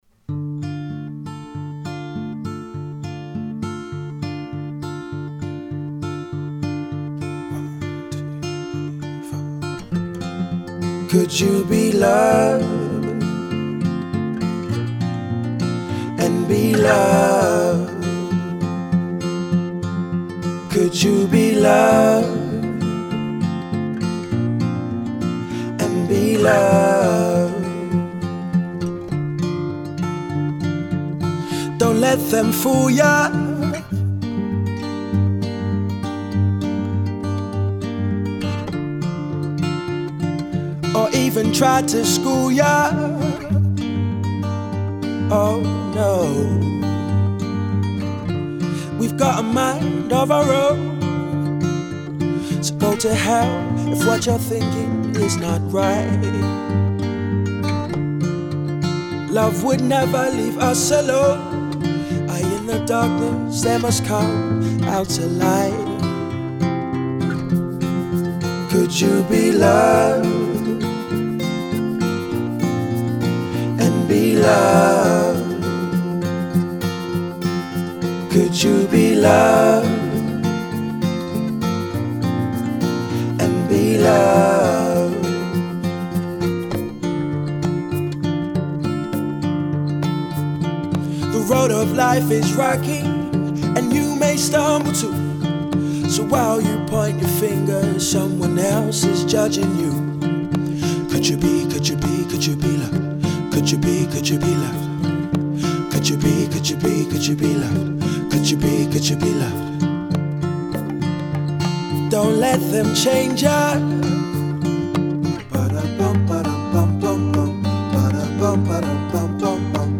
voice has very strong emotive abilities